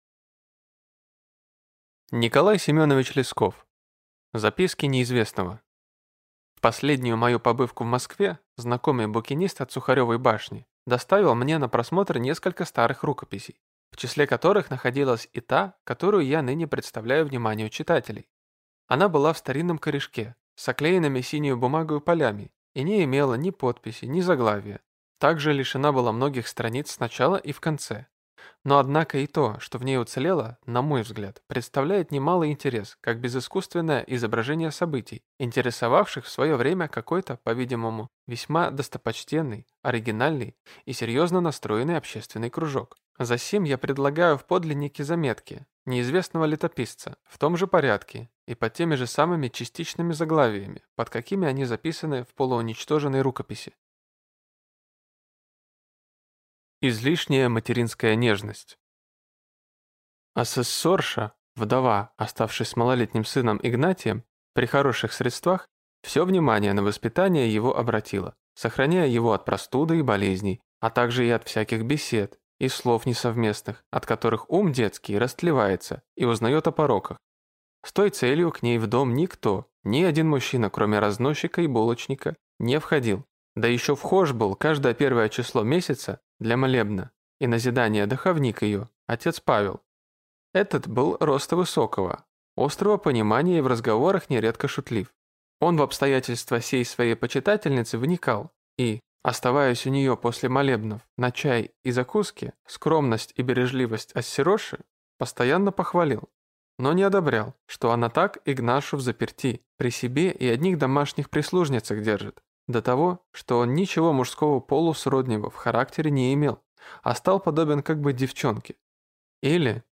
Аудиокнига Заметки неизвестного | Библиотека аудиокниг